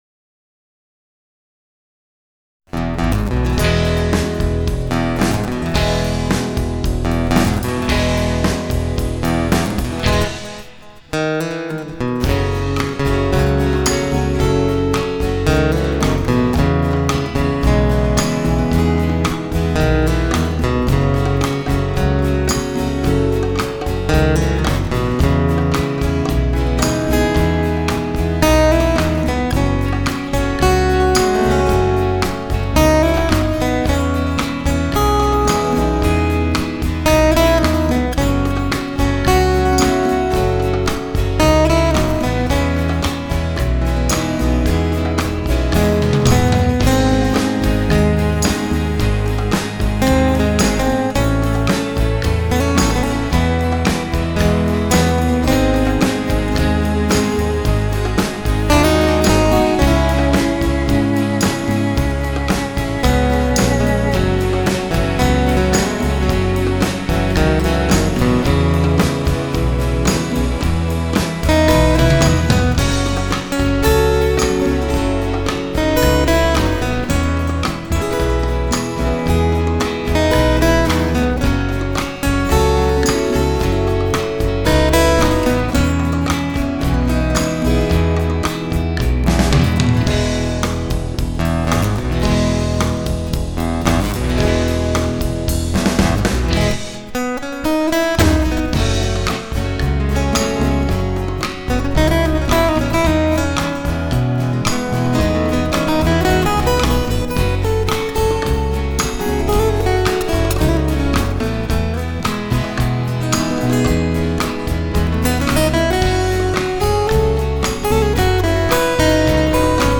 инструментального СЁРФ-РОКА
серф гитариста